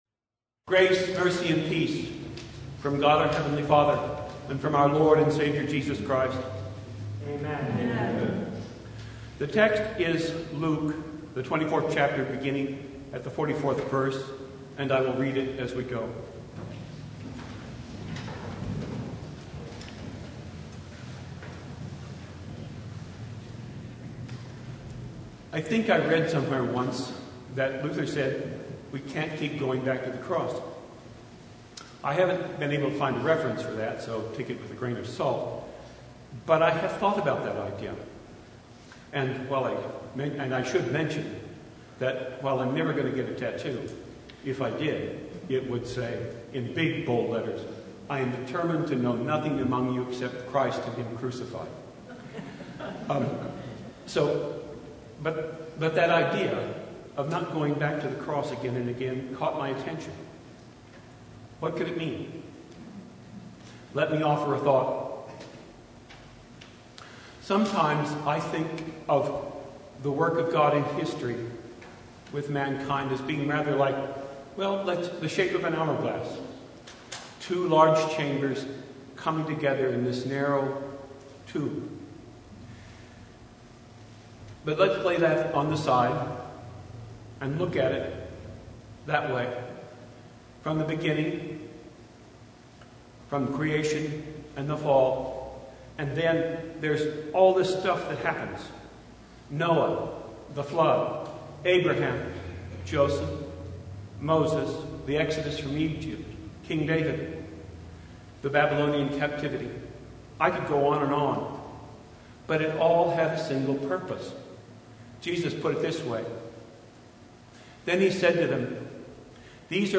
Sermon for the Ascension